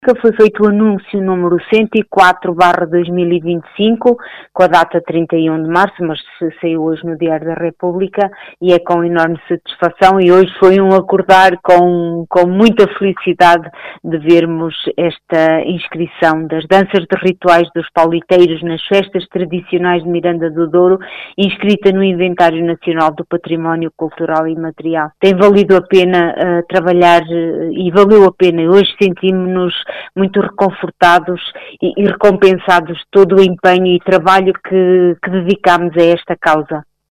A presidente de Miranda do Douro, Helena Barril, mostrou-se satisfeita com este marco: